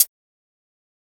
Closed Hats
HiHat (20).wav